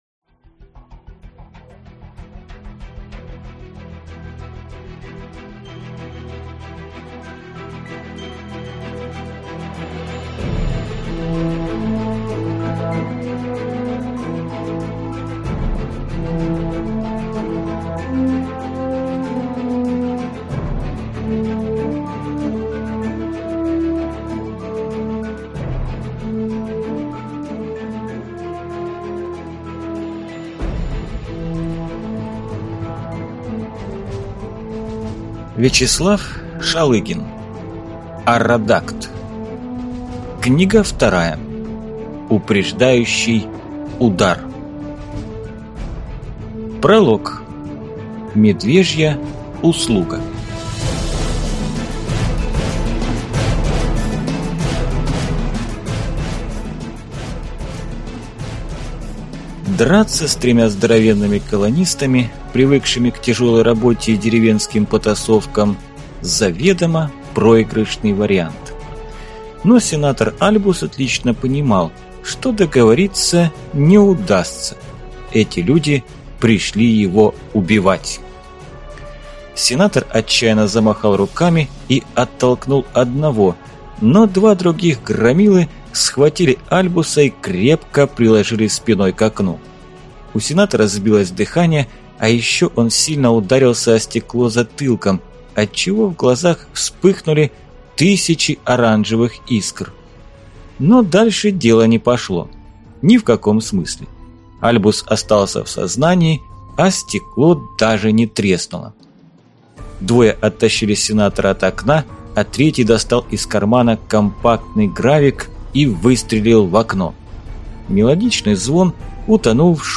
Аудиокнига Упреждающий удар | Библиотека аудиокниг
Читает аудиокнигу